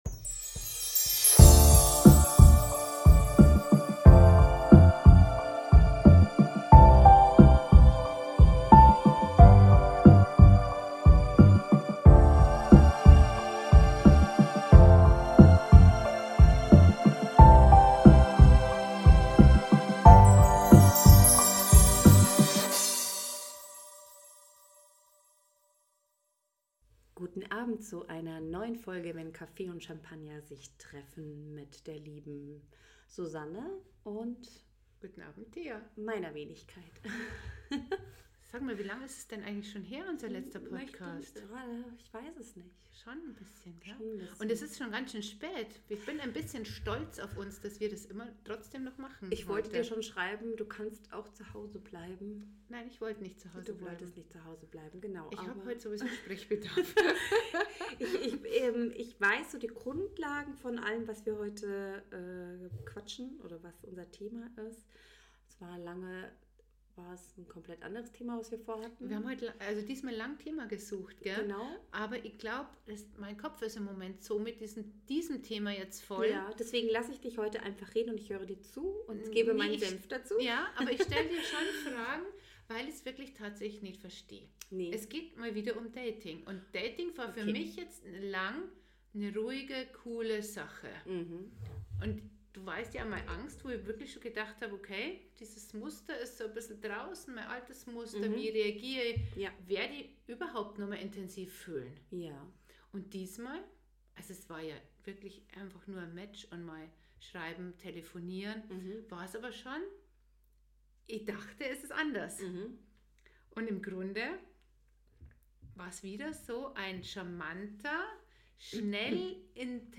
Zwei Frauen die dich in ihre Gedanken mitnehmen.